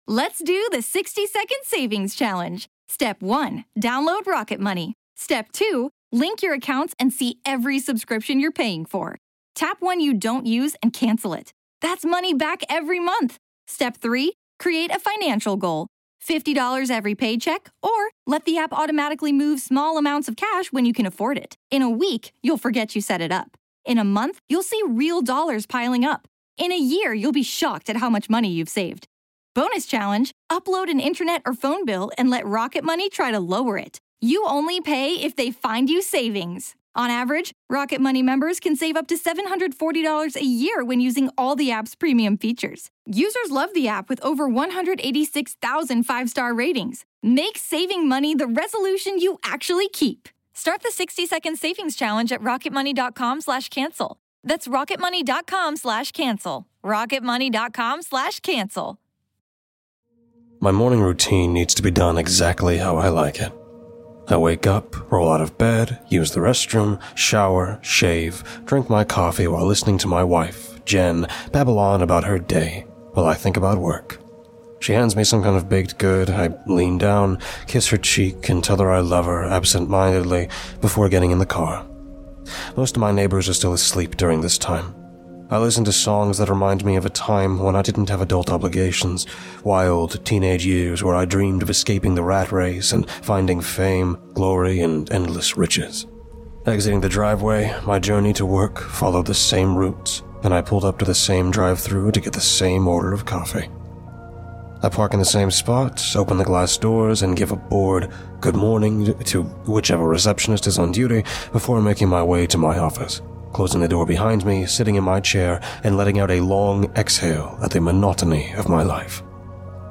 SFX Edited By Weekly Creeps: